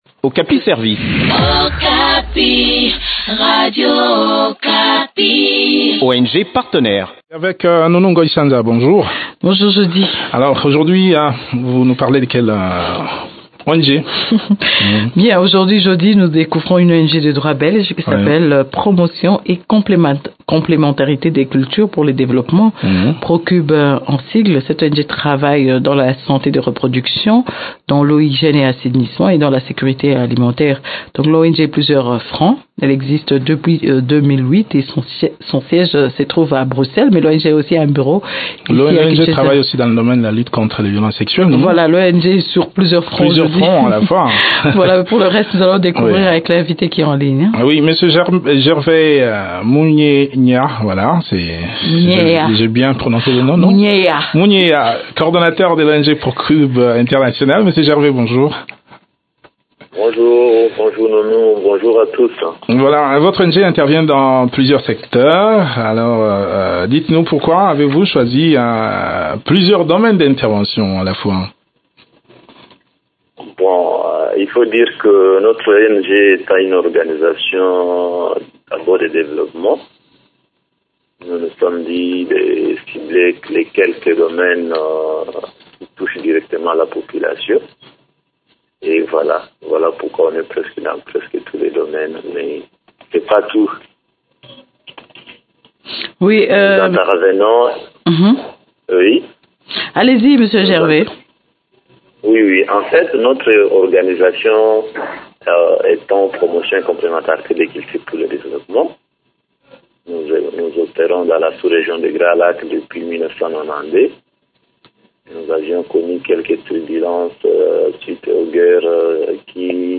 Elle organise aussi des programmes dans le secteur de la sécurité alimentaire et dans la lutte contre les violences sexuelles. Le point des activités de cette structure dans cet entretien